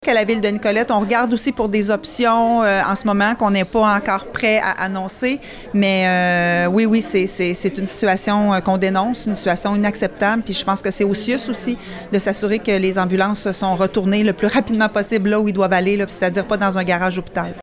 La mairesse, Geneviève Dubois, essaie d’établir un dialogue avec la CUISS MCQ pour éviter l’absence de services ambulanciers sur son territoire.